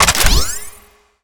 sci-fi_weapon_reload_06.wav